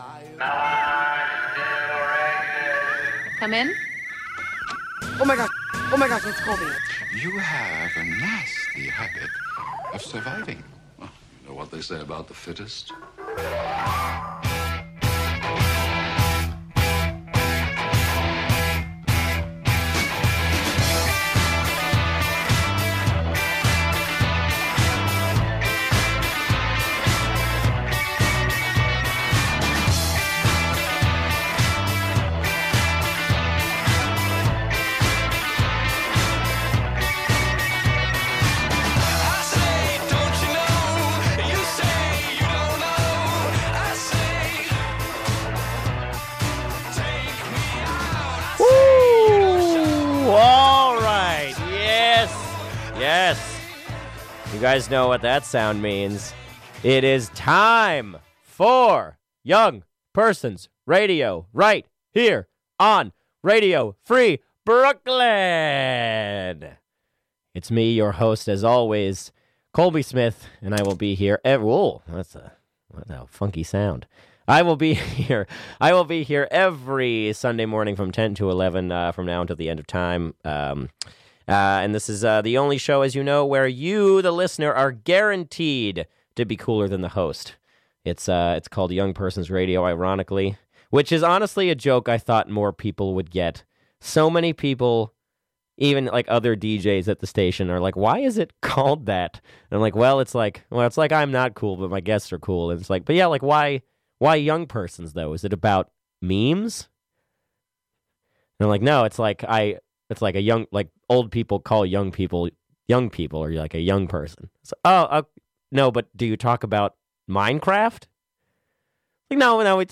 Let's all hope she calls back again!